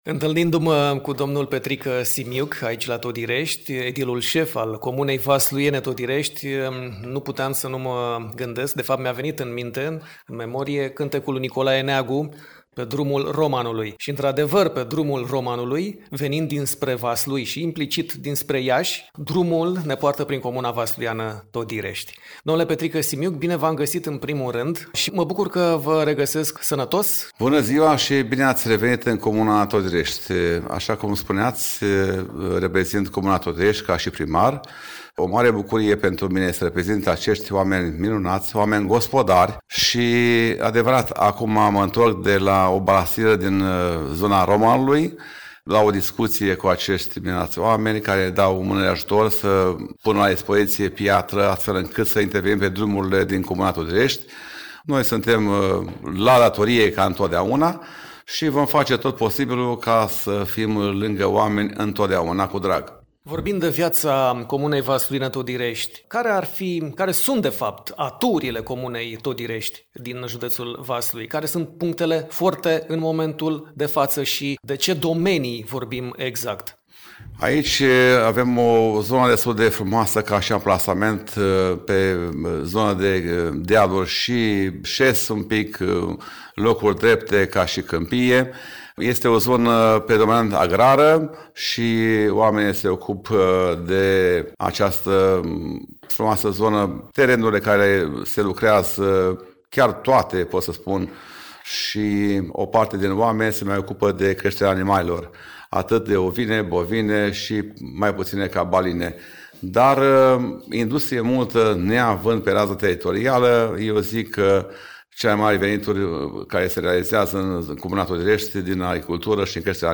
Viața din prezent a comunei Todirești, din județul Vaslui, ne este descrisă de Petrică Simiuc, edilul șef al localității
Sursă: Petrică Simiuc, primarul comunei Todirești, județul Vaslui.